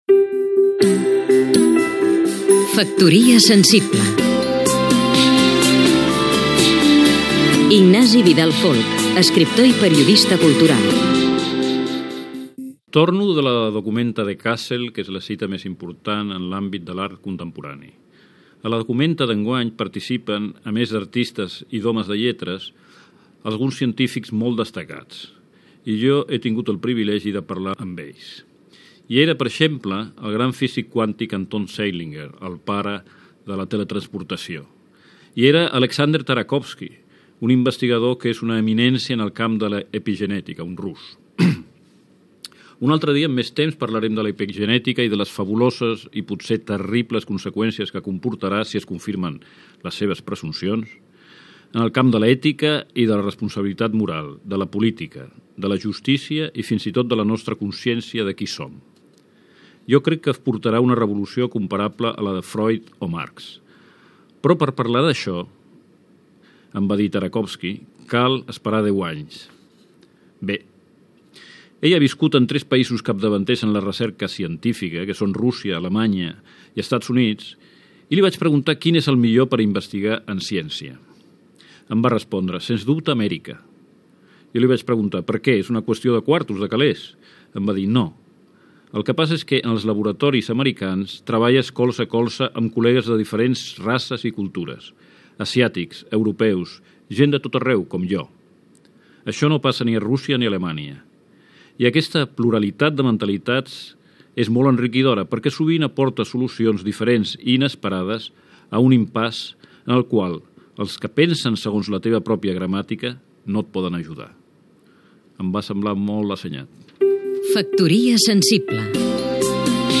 Careta del programa.
FM